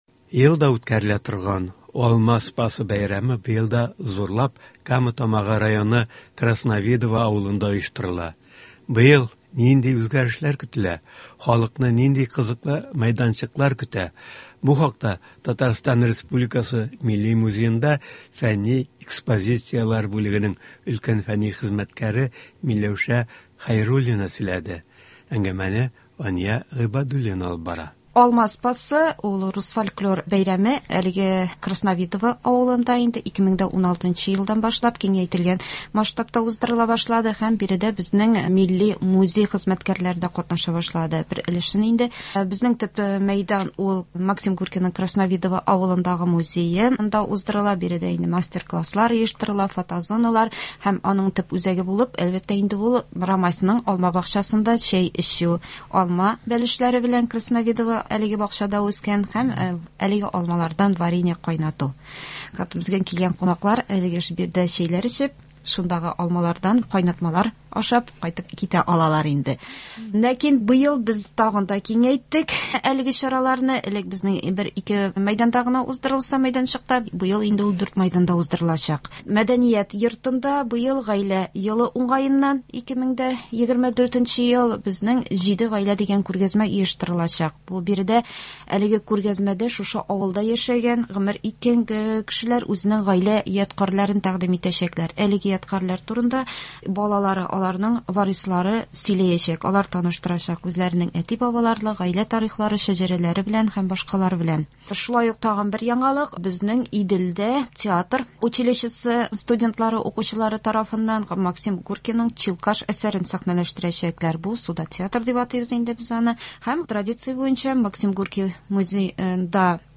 Татарстан авыл хуҗалыгы һәм азык-төлек министры урынбасары Рафаэль Мәхмүт улы Фәттахов турыдан-туры эфирда шул хакта сөйләячәк, тыңлаучыларыбызга җавап бирәчәк.